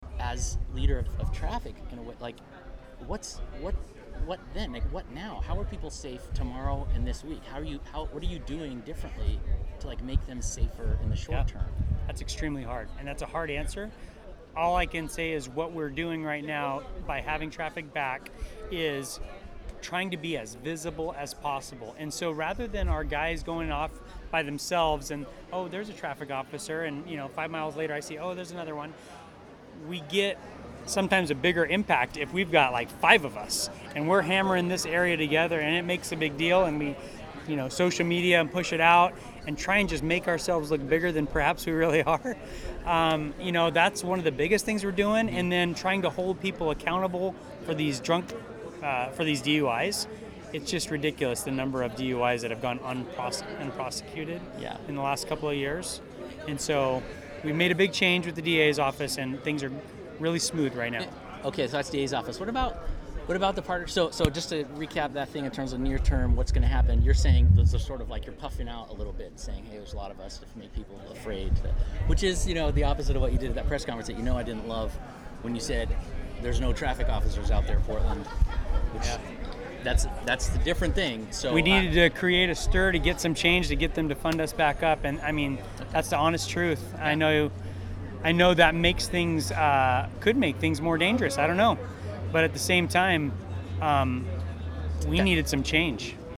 I didn’t plan to ask him about it again at Monday morning’s press conference.